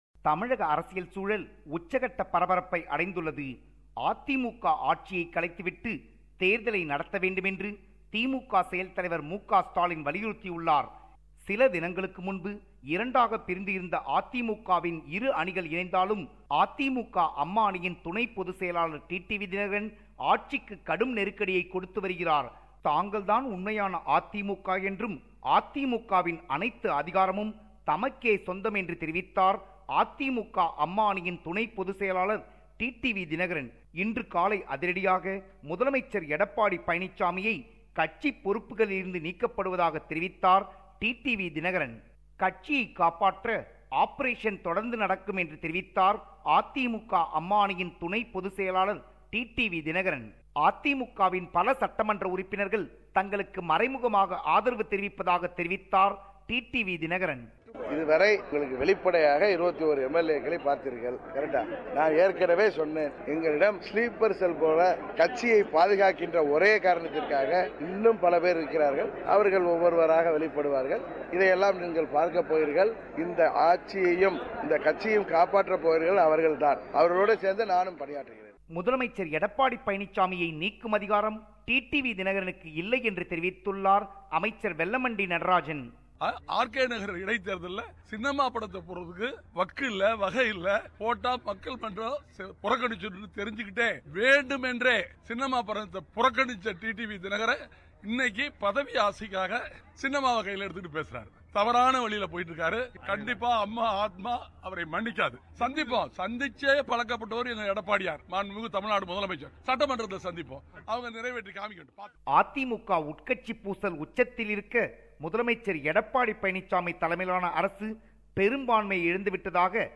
Tamil News